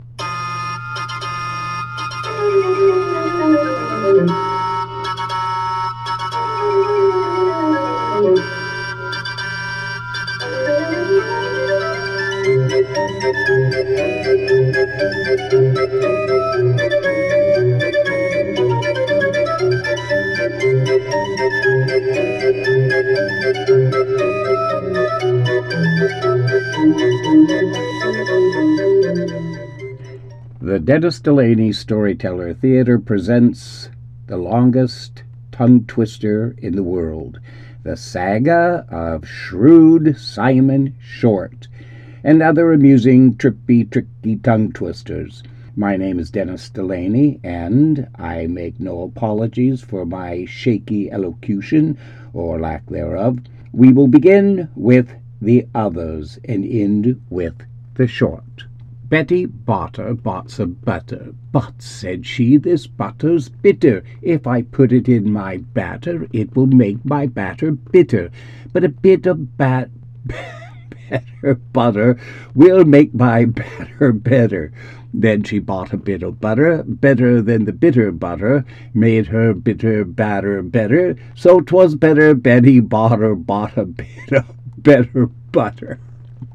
The-Longest-Tongue-Twister-in-the-world-Preview.mp3